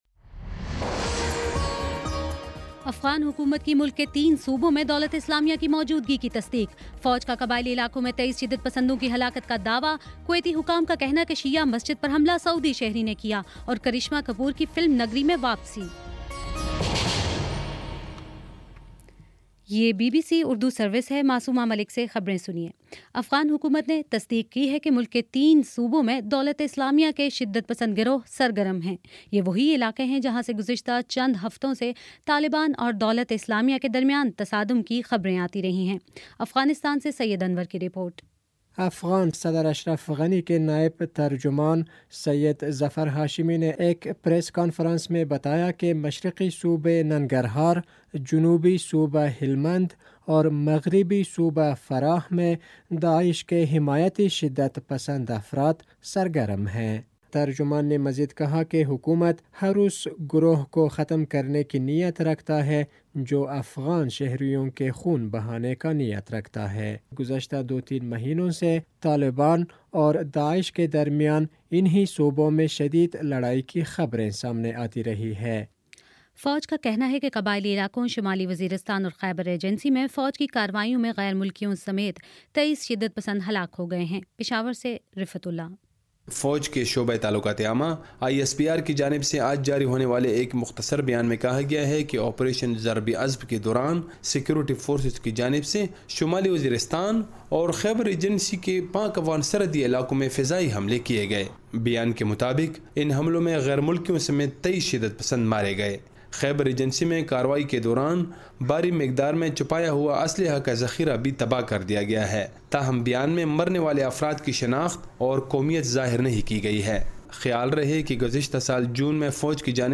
جون 28: شام سات بجے کا نیوز بُلیٹن